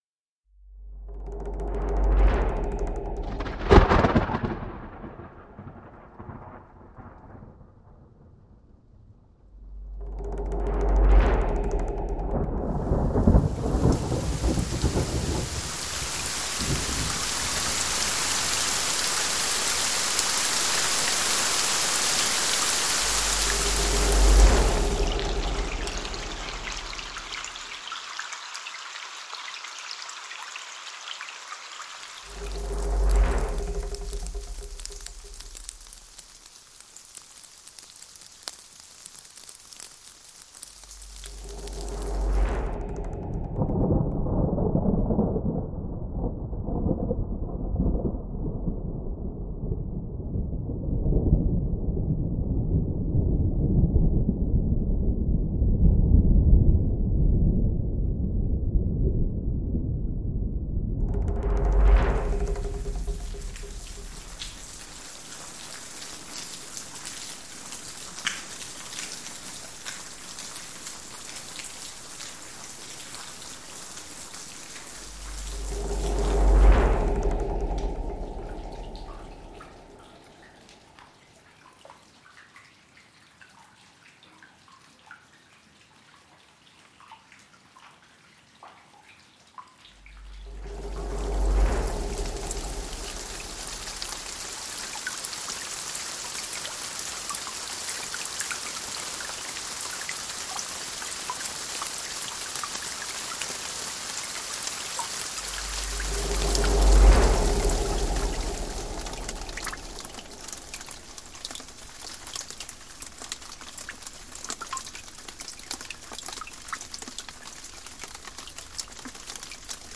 音效素材-66组下雨狂风暴雨打雷闪电环境环绕立体声音效 Thunder & Rain
6组打雷下雨狂风闪电暴雨环境环绕立体声音效，拥有一系列高质量和惊人的自然SFX音效。22个孤立的雷声录音，包括近身打击，共振皮和远处摇摆；加上35种不同的降雨强度，以及9种风暴记录。
音效关键词：雷，雨，性质，滚动，剥皮，遥远，附近，倾盆大雨，阵雨，水滴，滴，P沥沥，树叶，风暴，飞溅，深，戏剧性，水下，洞穴，罢工，共振，暴雨
Thunder-Rain-试听.mp3